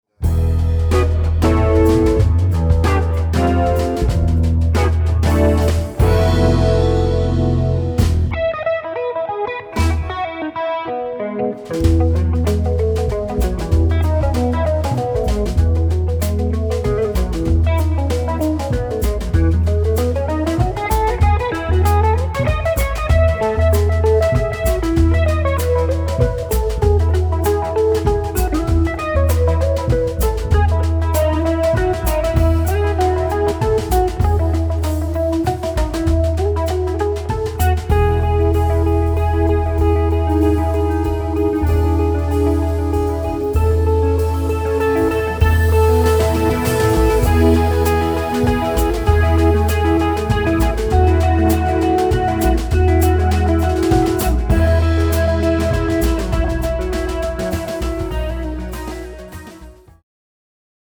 famous classical themes with classic grooves.